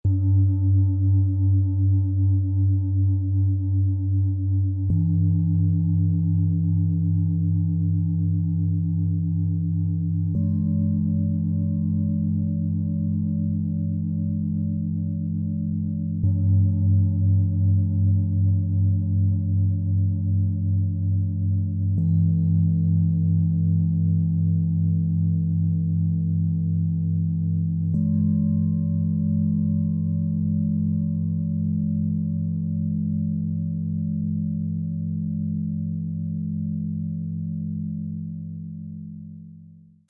Erden, Herz öffnen, den eigenen Bedürfnissen folgen - klar denken und sprechen - Set aus 3 Planetenschalen mit Solfeggio, Ø 16,5 -24,4 cm, 2,77 kg
Dieses Set verbindet tiefe Erdung, Herzöffnung und mentale Klarheit zu einem harmonischen Klangfeld, das Körper, Herz und Geist gleichermaßen stärkt.
Tiefster Ton: Tageston und Solfeggio 285 Hz – Erdung und energetische Stärkung
Mittlerer Ton: Hopi, Mond, Solfeggio 852 Hz – Herzöffnung und intuitive Anbindung
Höchster Ton: Merkur – Klare Gedanken und Ausdruckskraft
Im Sound-Player - Jetzt reinhören lässt sich der Original-Ton genau dieser drei Schalen anhören. Ihre Schwingungen entfalten eine harmonische, wohltuende Resonanz.
Bengalen-Schale, matt, Durchmesser 24,4 cm, Höhe 9,3 cm